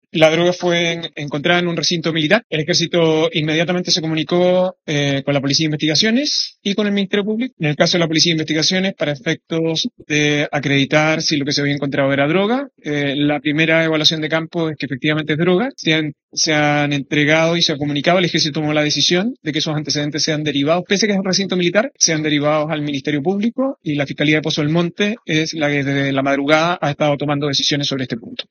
El ministro de Seguridad Pública, Luis Cordero, confirmó este jueves el hallazgo de droga al interior de una unidad militar ubicada en la comuna fronteriza de Colchane, en la región de Tarapacá.